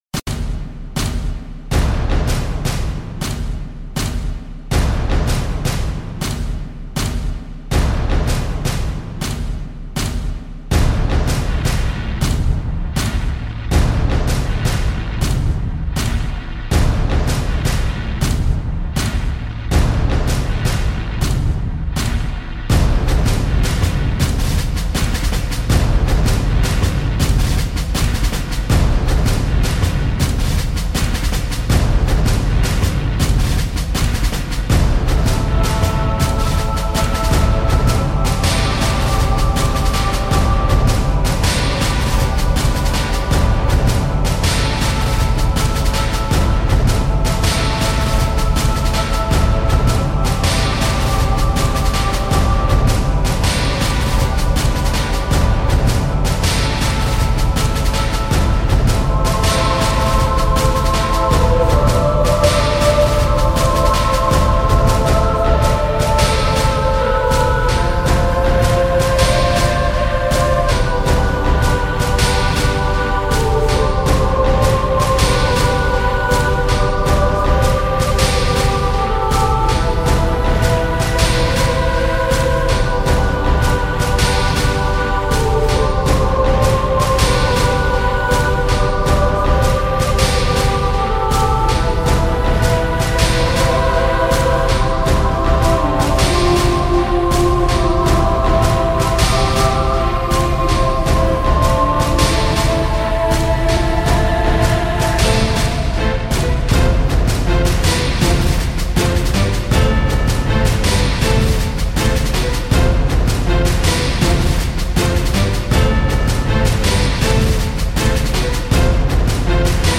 i wanted to do something strong and epic